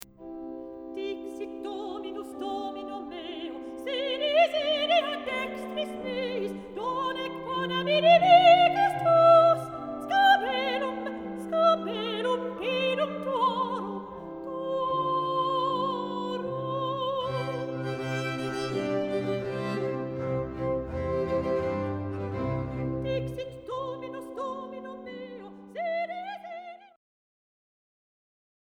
S tai T
jouset ja bc